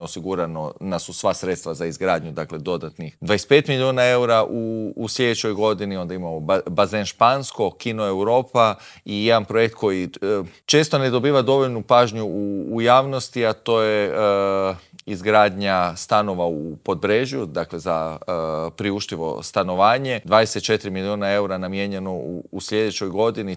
O ovim i drugim pitanjima smo u Intervjuu tjedna Media servisa razgovarali s predsjednikom zagrebačke Gradske skupštine, Matejem Mišićem.